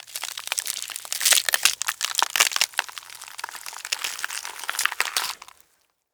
Flesh Impact Sound
horror